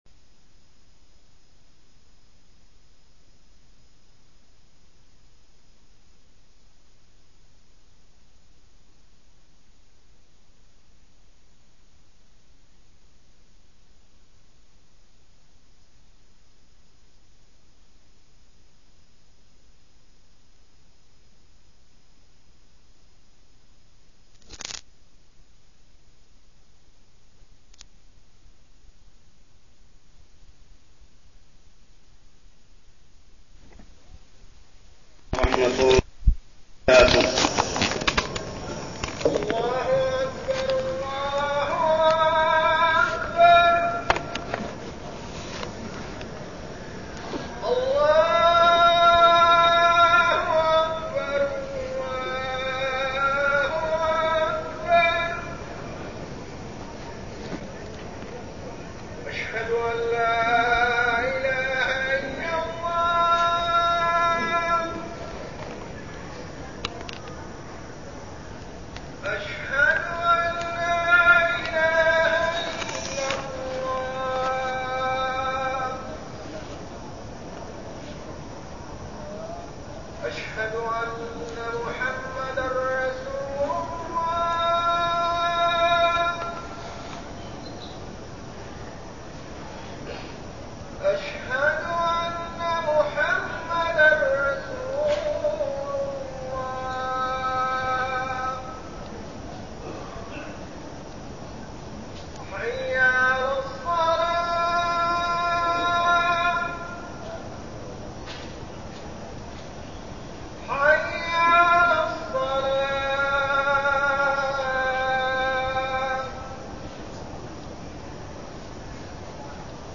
تاريخ النشر ٢٨ رجب ١٤١٠ هـ المكان: المسجد الحرام الشيخ: محمد بن عبد الله السبيل محمد بن عبد الله السبيل الدعوة إلى الله على طريقة الرسول صلى الله عليه وسلم